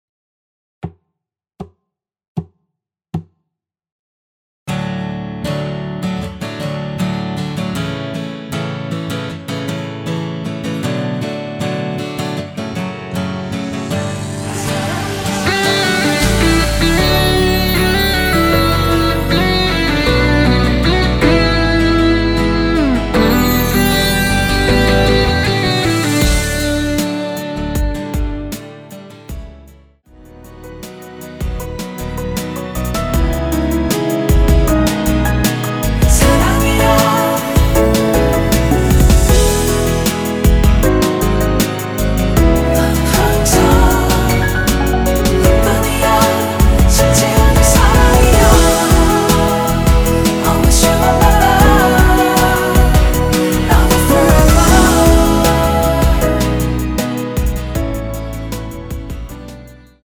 전주 없이 시작하는 곡이라서 노래하기 편하게 카운트 4박 넣었습니다.(미리듣기 확인)
원키에서(-2)내린 코러스 포함된 MR입니다.
앞부분30초, 뒷부분30초씩 편집해서 올려 드리고 있습니다.